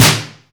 • Dirty Steel Snare Drum Sound D# Key 142.wav
Royality free steel snare drum tuned to the D# note. Loudest frequency: 3615Hz
dirty-steel-snare-drum-sound-d-sharp-key-142-FiR.wav